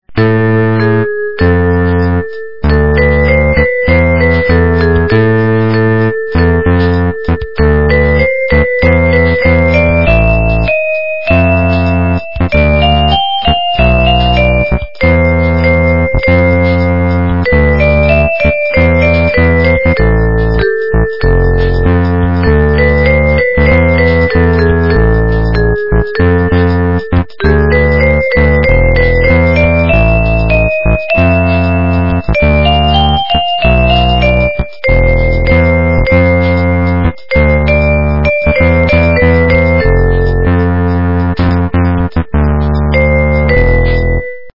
качество понижено и присутствуют гудки.
полифоническую мелодию